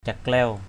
/ca-klɛʊ/ (d.) cá tràu vừa.